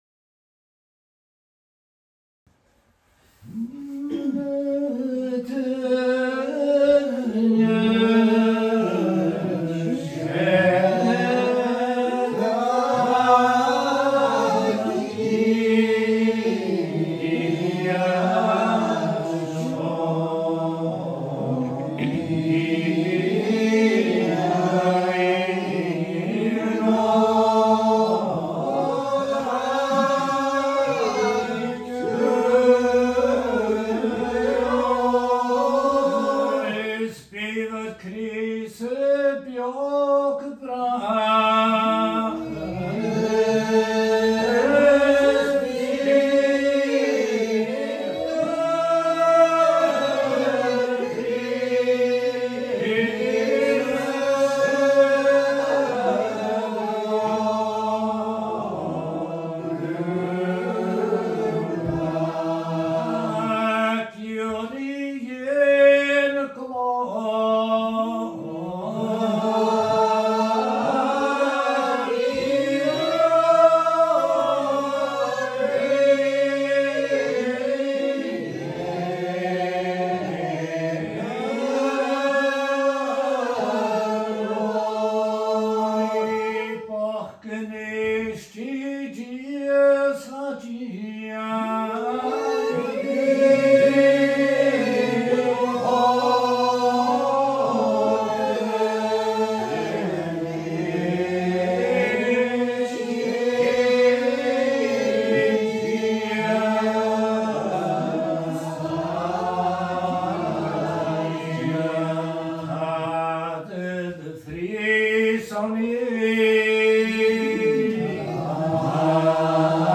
A’ Seinn nan Sailm Gaelic Psalmody